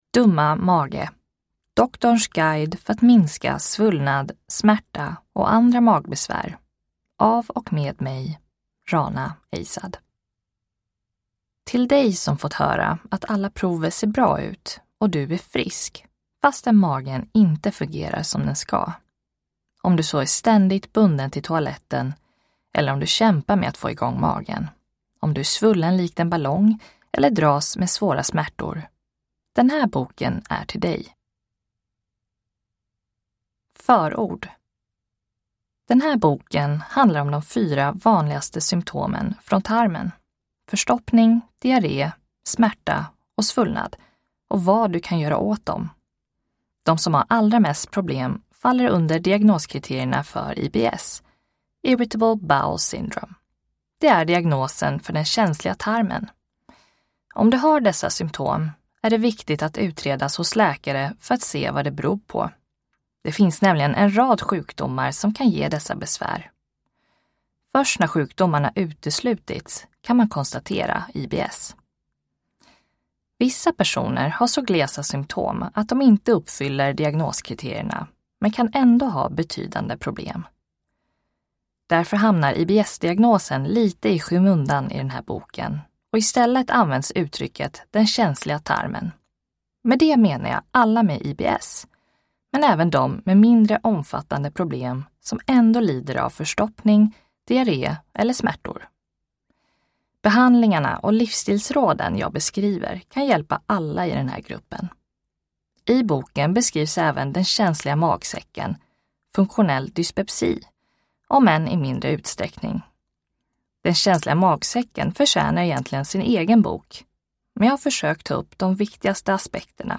Dumma mage : doktorns guide för att minska svullnad, smärta och andra magbesvär – Ljudbok